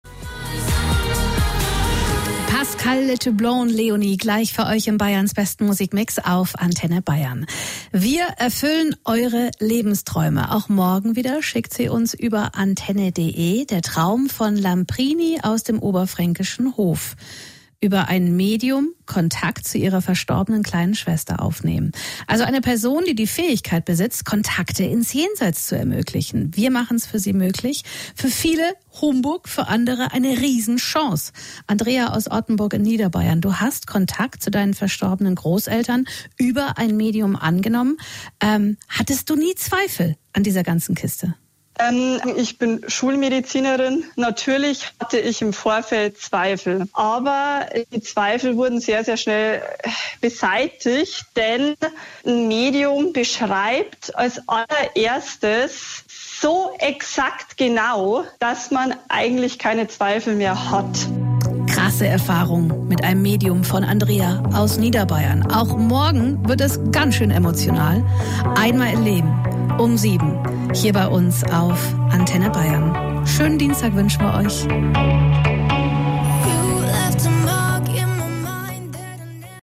Interview-Klientin-2-1.mp3